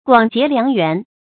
广结良缘 guǎng jié liáng yuán 成语解释 多做善事，以得到众人的赞赏。